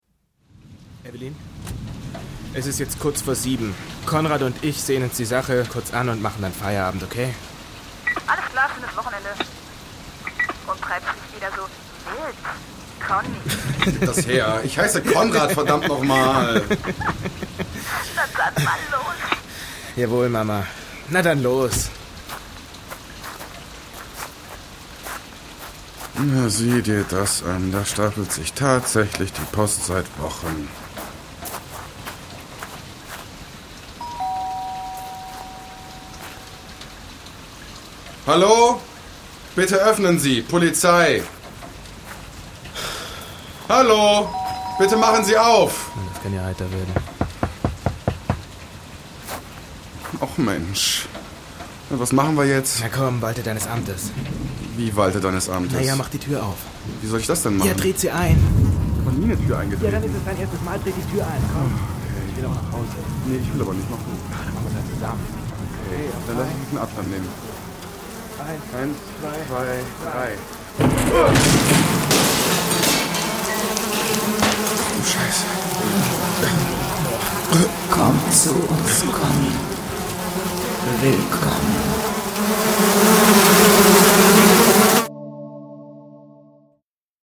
deutscher Sprecher
Sprechprobe: Industrie (Muttersprache):
german voice over artist